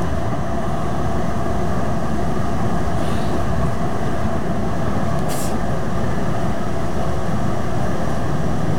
transportvehicle.ogg